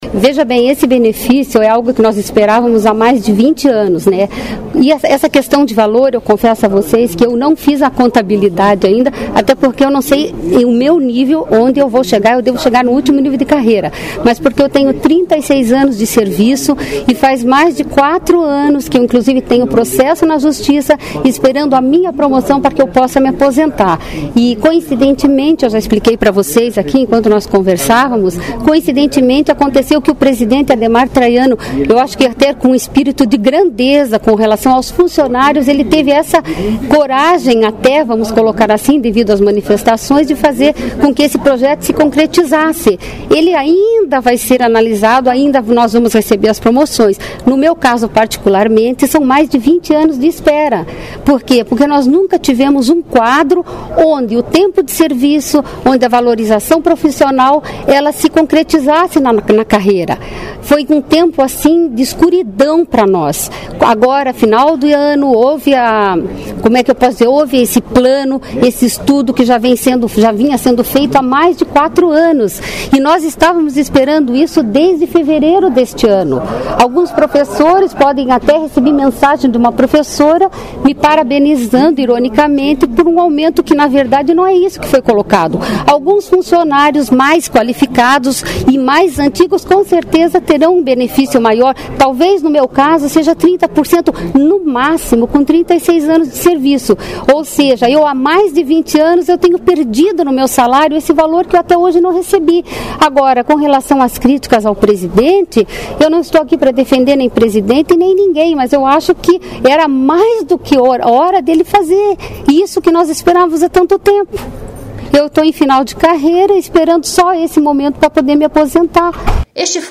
Servidores e presidente da Assembleia esclarecem a jornalistas o significado das progressões nas carreiras
(sonora)
O presidente Ademar Traiano também voltou a explicar aos jornalistas que a correção não é um benefício, mas a regularização de situações que se estendem por anos.